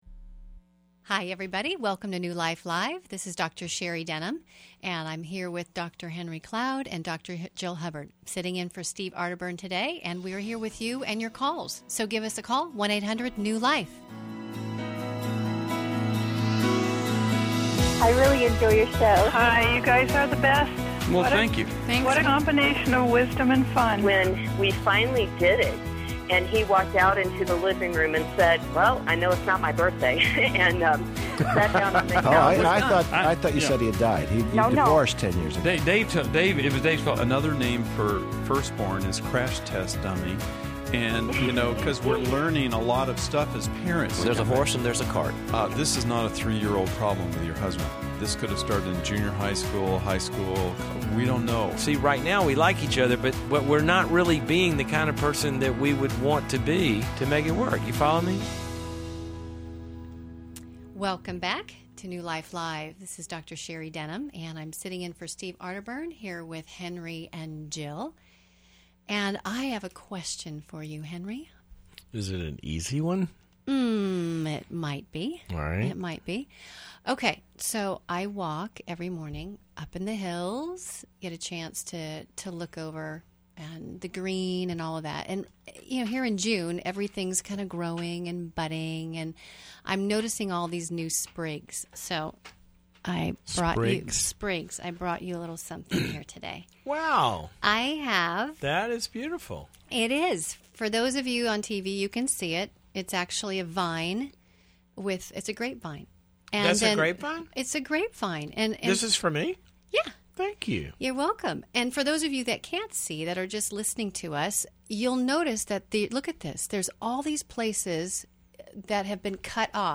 Caller Questions: 1. My husband is drinking daily since being unemployed. 2.